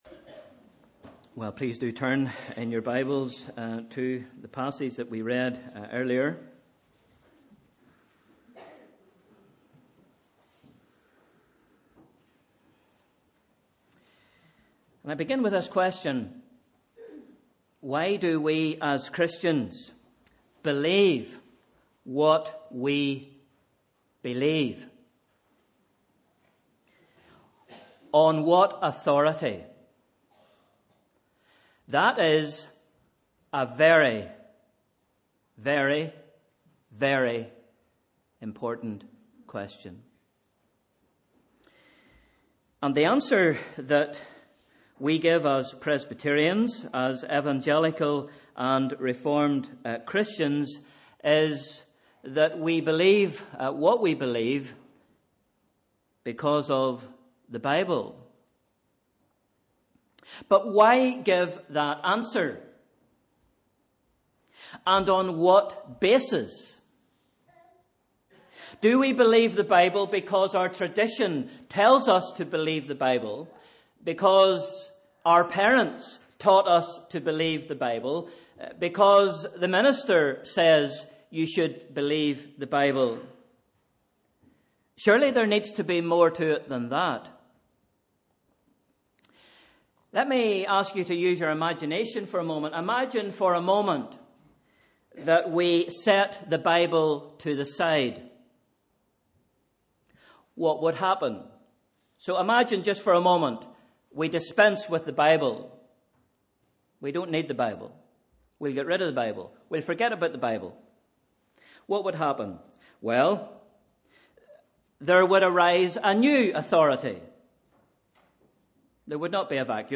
Sunday 15th November 2015 – Morning Service